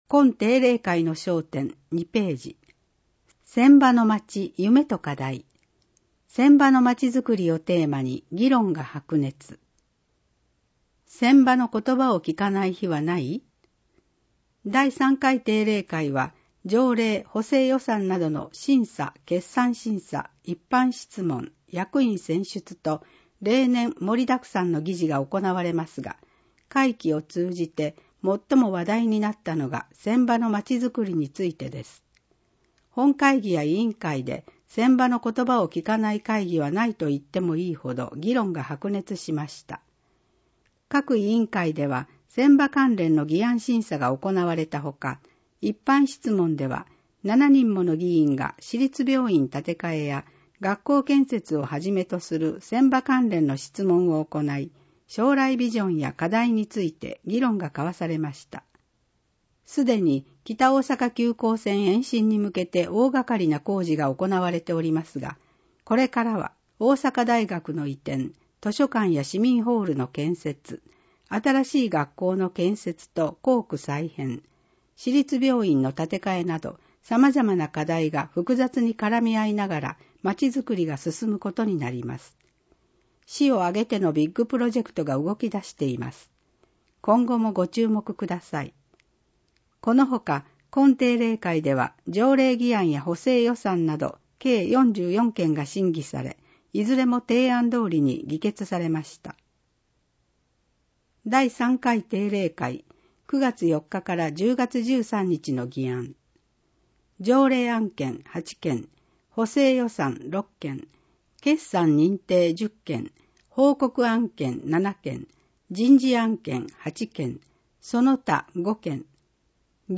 みのお市議会だより「ささゆり」の内容を声で読み上げたものを掲載しています。